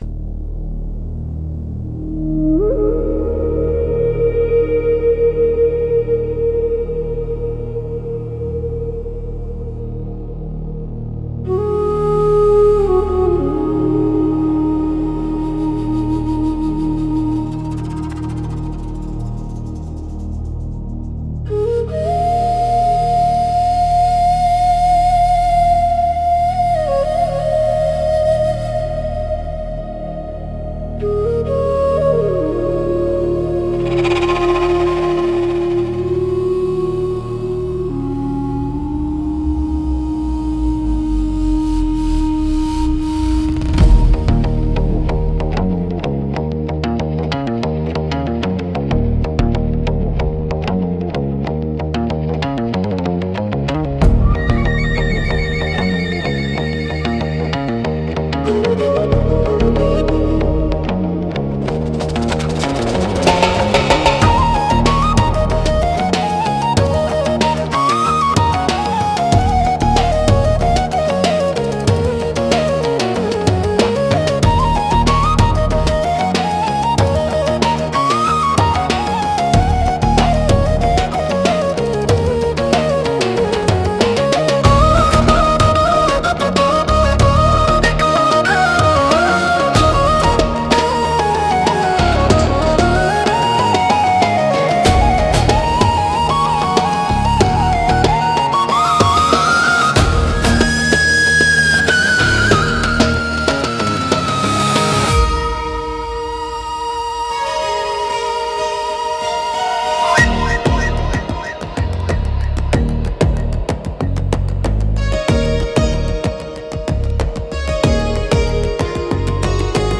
Instrumentals - The Final Cèilidh of Rust